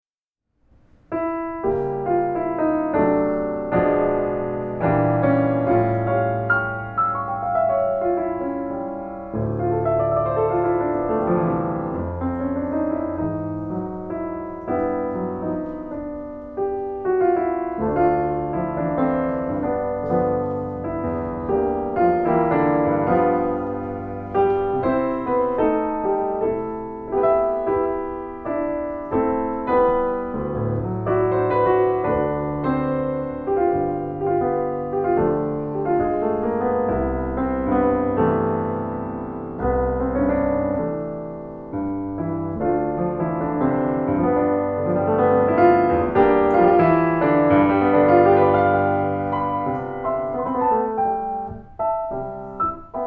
This mp3 shows a lot of woodness and warm sound.